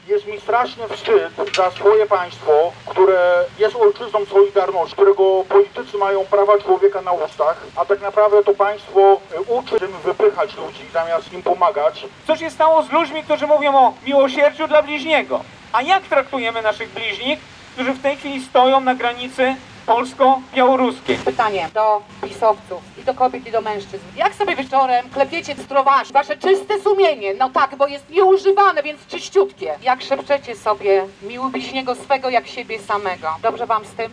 Wczorajszego popołudnia na placu Adamowicza w Szczecinie zebrali się aktywiści organizacji wolnościowych, aby zaprotestować przeciwko działaniom polskiego rządu na granicy polsko-białoruskiej. Zebrani mogli wysłuchać wystąpień lokalnych liderów Lewicy Razem, Stowarzyszenia Polska 2050 i Strajku Kobiet.
W strugach deszczu demonstrowało kilkadziesiąt osób.